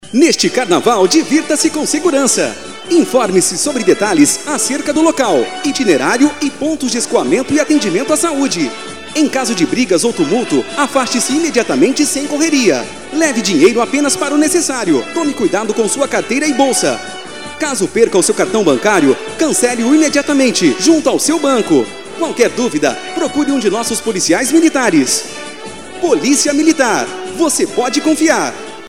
Policia-Militar-spot-rádio.mp3